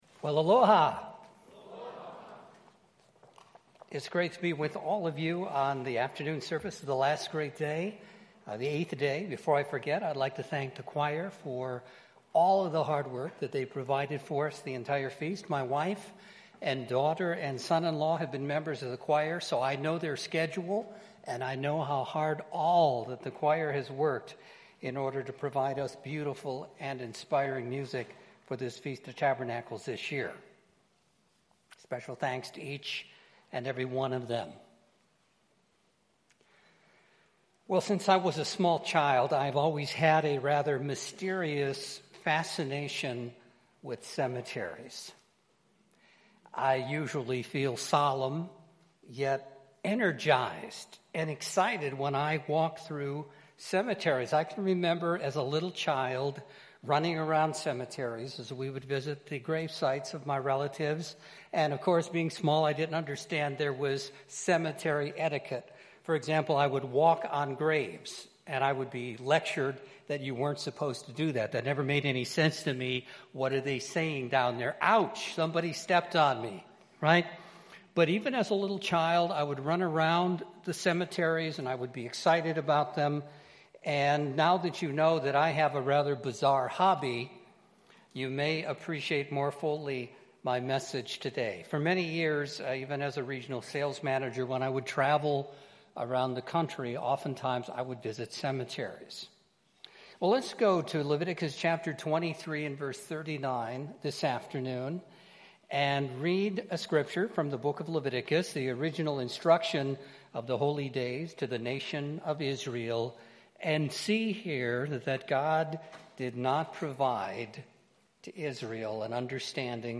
This sermon was given at the Lihue, Hawaii 2014 Feast site.